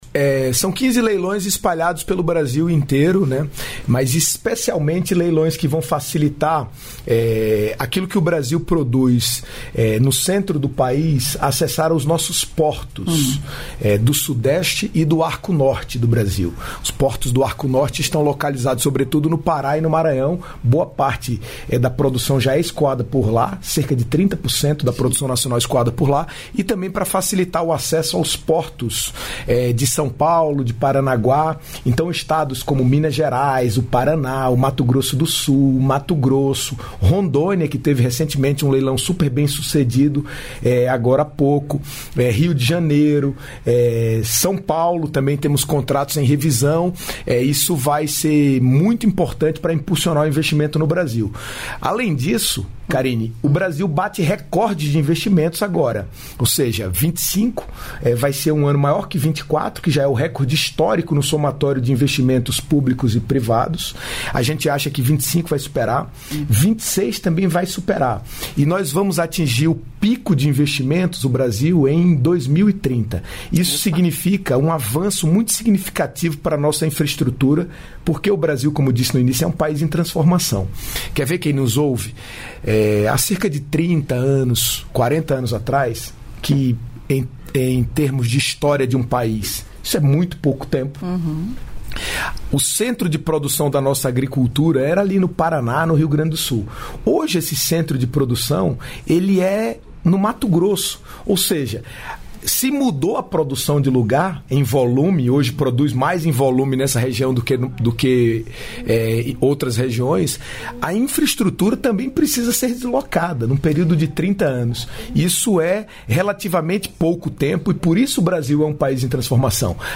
Trecho da participação do ministro dos Transportes, Renan Filho, no programa "Bom Dia, Ministro" desta quinta-feira (10), nos estúdios da EBC em Brasília (DF).